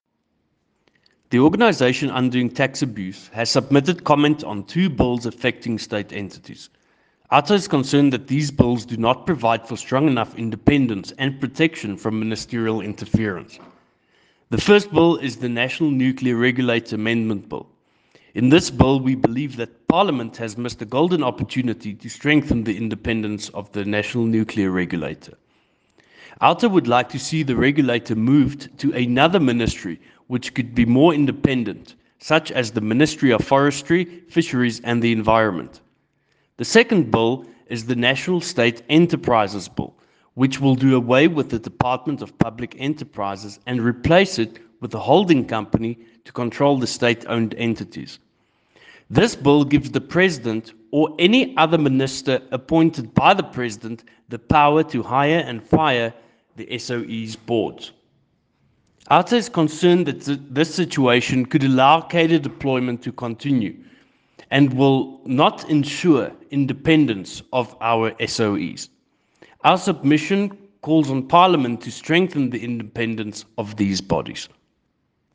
A voicenote with comment